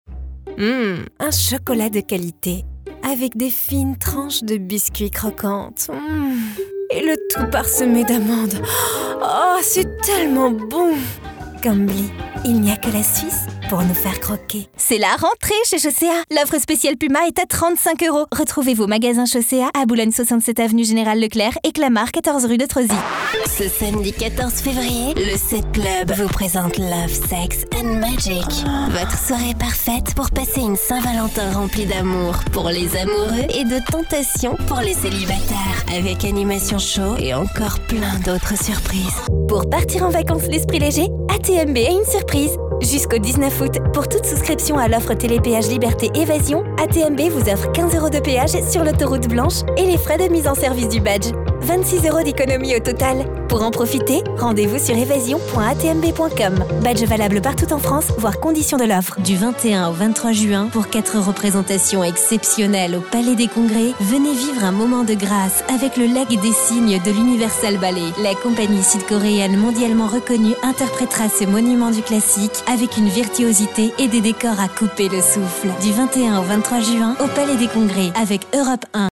Voix Off féminine
Medley Spots Radio - Voix Off
C'est au cœur de mon studio d'enregistrement professionnel, que je vous propose un enregistrement de grande qualité.